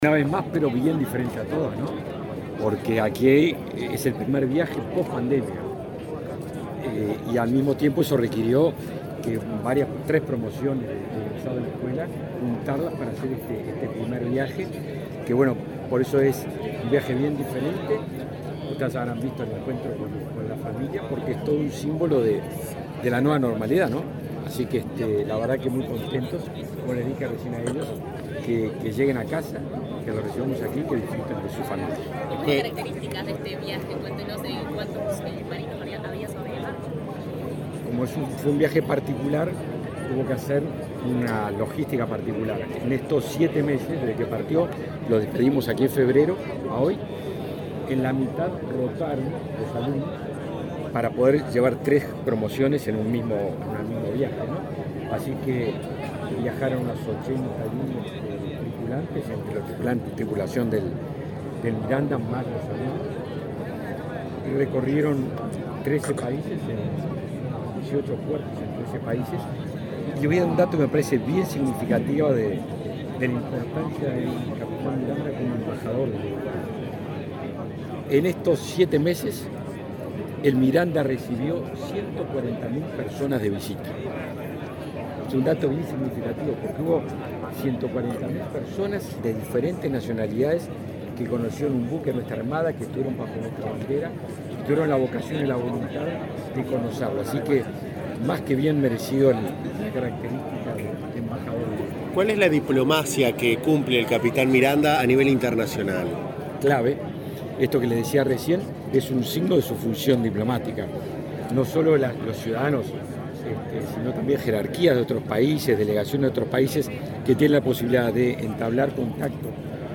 Declaraciones del ministro de Defensa, Javier García
Este lunes 5, en el puerto de Montevideo, el ministro de Defensa Nacional, Javier García, recibió al buque escuela Capitán Miranda, que retornó de su